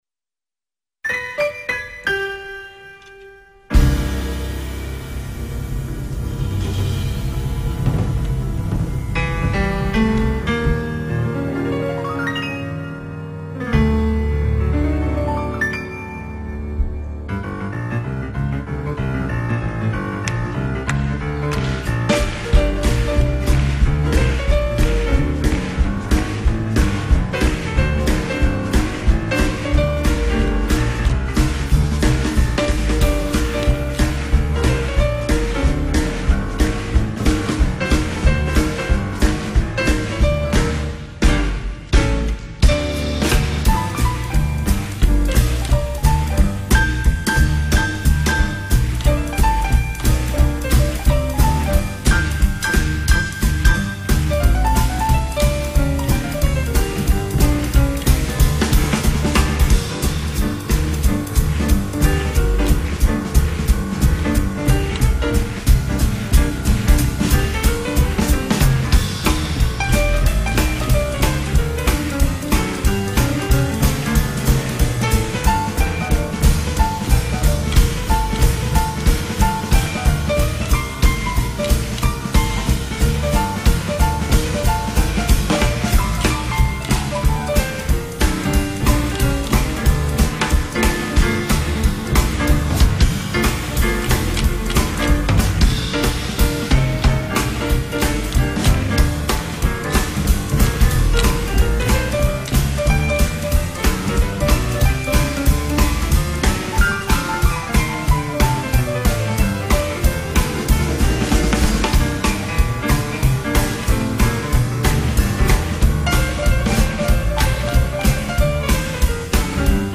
Спасибо за поздравления, и  за чУдный блюз !